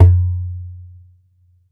ASHIKO SUP0U.wav